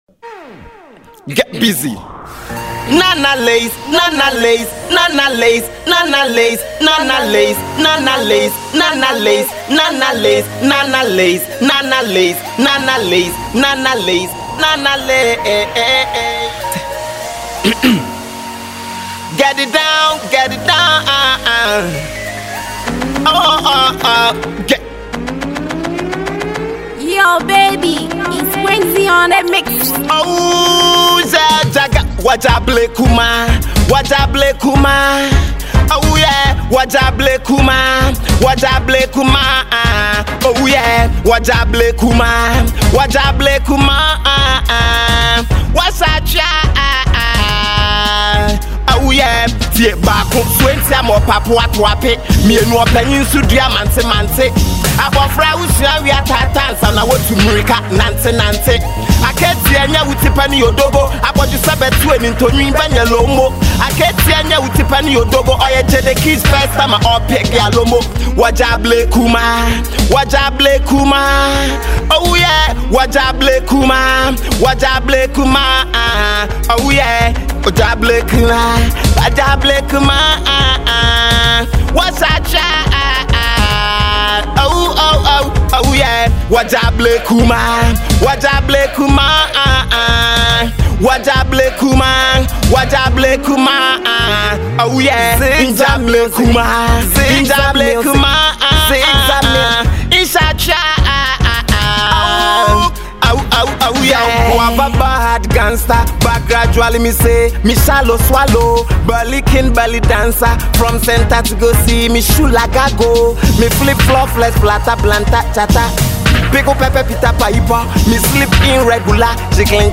Rising Ghanaian rapper